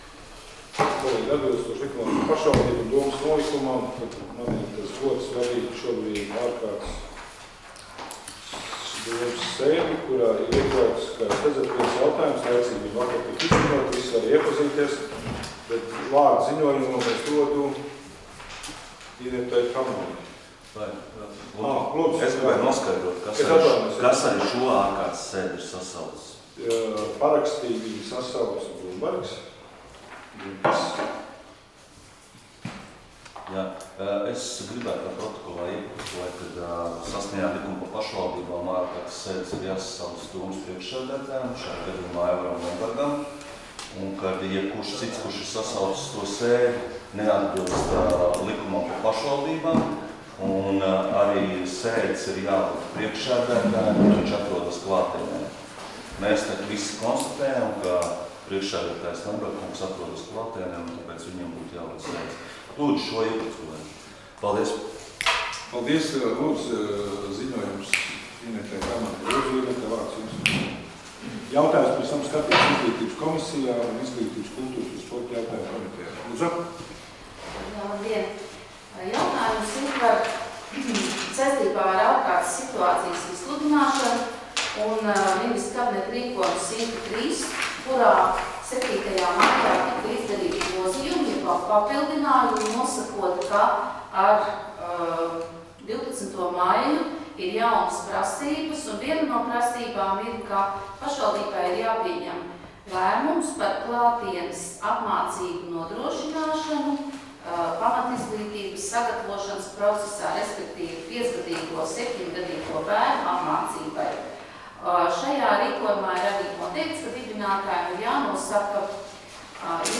Domes ārkārtas sēdes 18.12.2020. audioieraksts